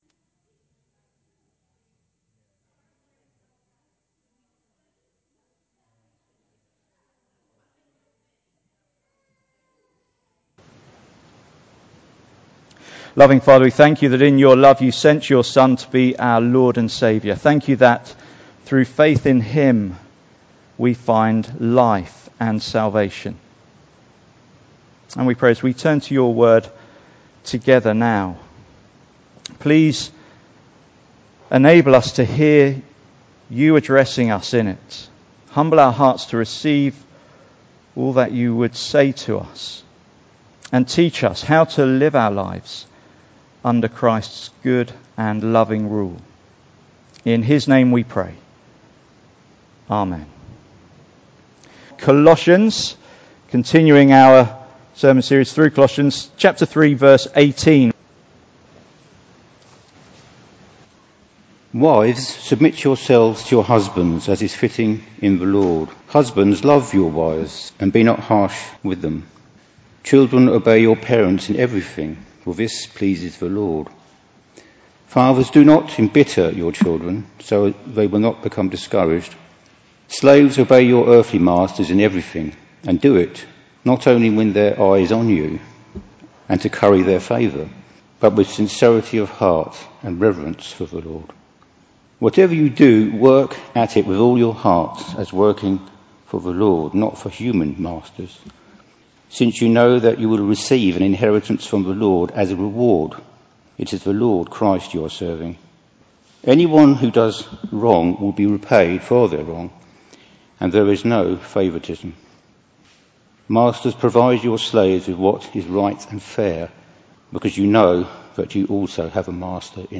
Passage: Colossians 3:18-4:1 Service Type: Sunday Morning